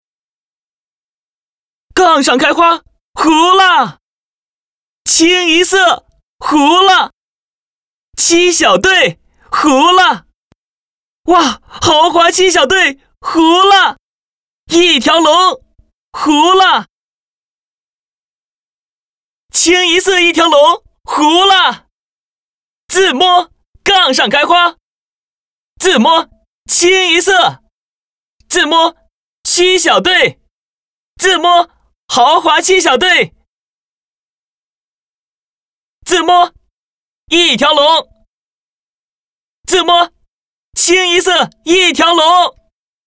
年轻角色扮演配音员 棋牌配音 华为手机广告配音
年轻时尚 自然诉说 成熟厚重 大气浑厚 成熟厚重
229-棋牌-杠上开花.mp3